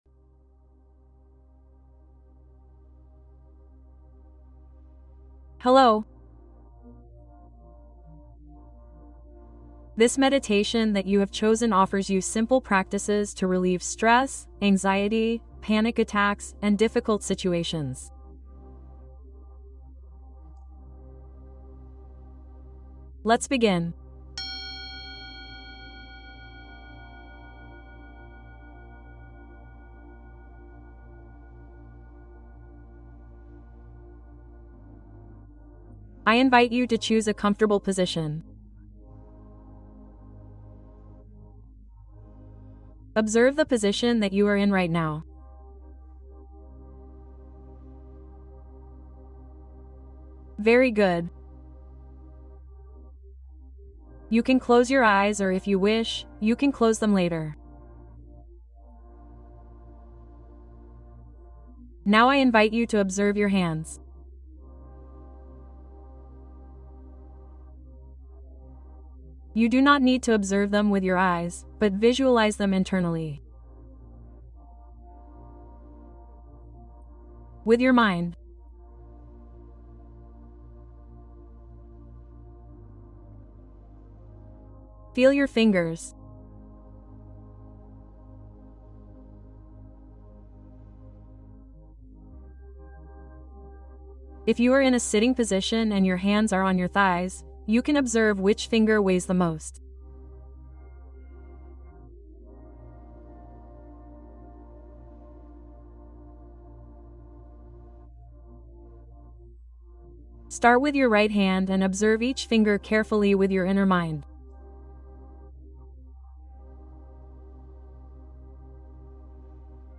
Acompañar la ansiedad con atención plena: meditación guiada